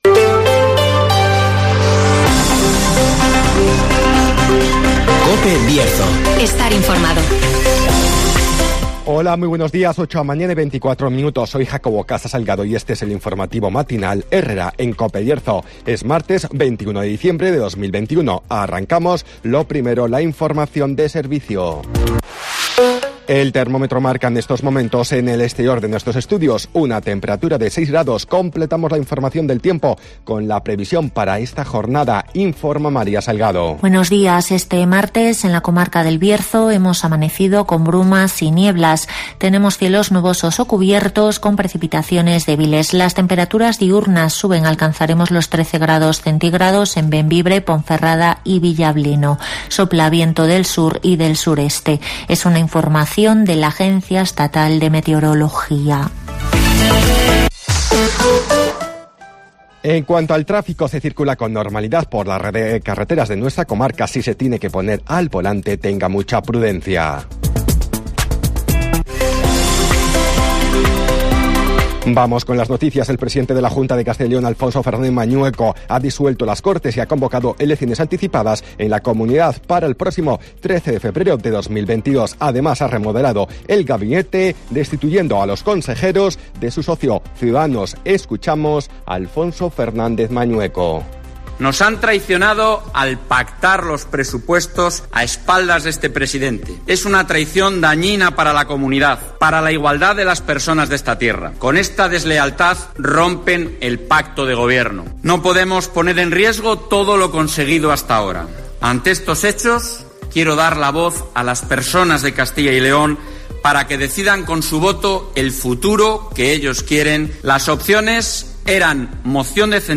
INFORMATIVOS
Repaso a la actualidad informativa del Bierzo. Escucha aquí las noticias de la comarca con las voces de los protagonistas.